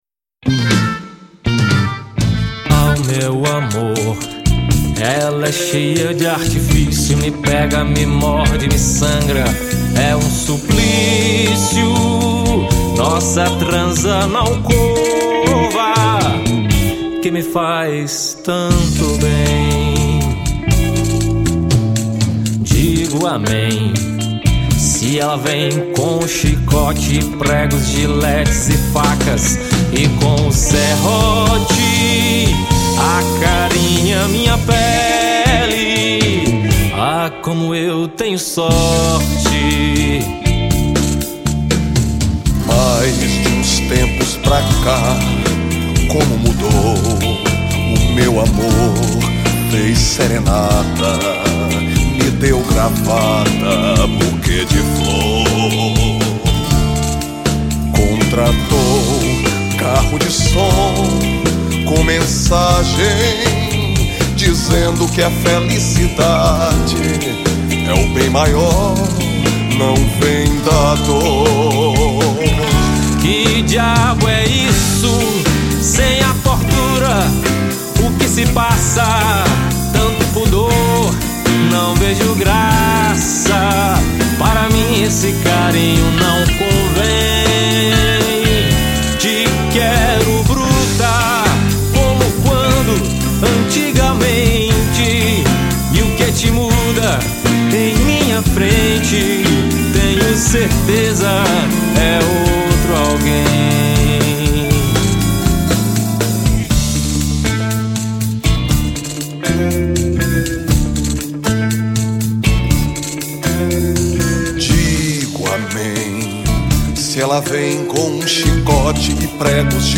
1923   04:15:00   Faixa:     Rock Nacional